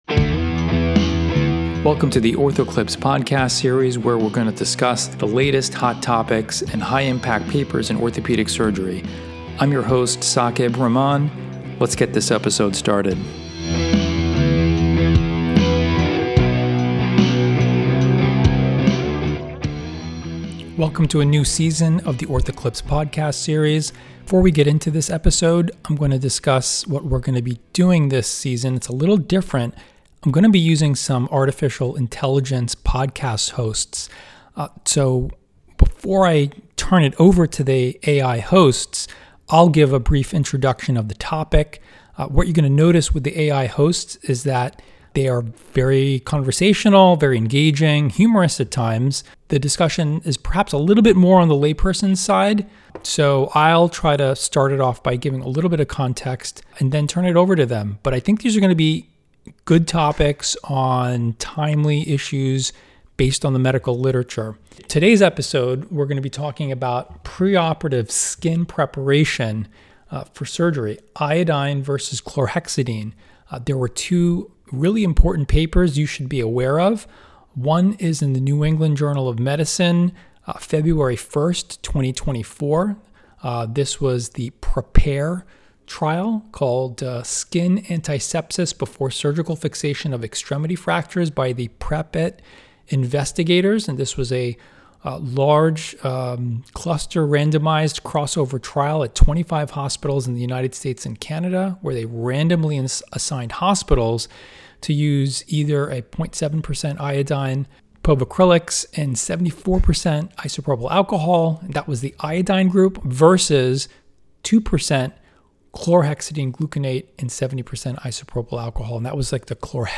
Results from both trials are discussed and in this season, we are introducing some surprisingly engaging, artificial intelligence hosts.